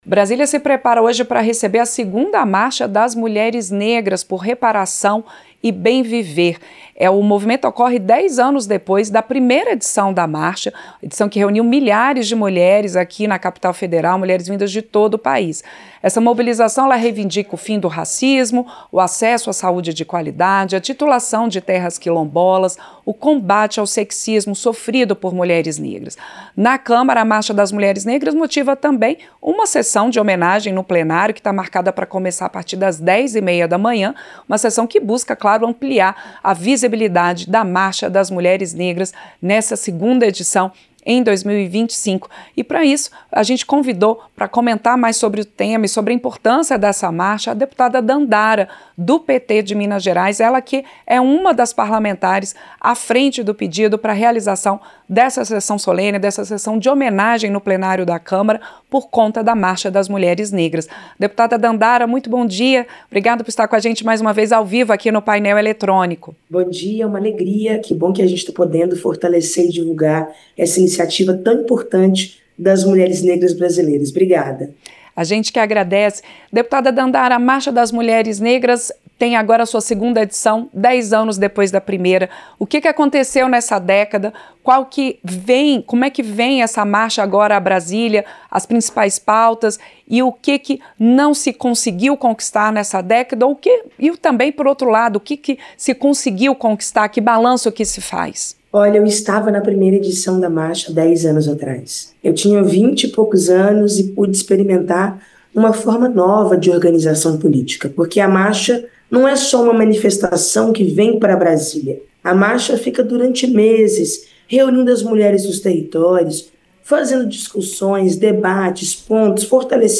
Entrevista -Dep. Dandara (PT-MG)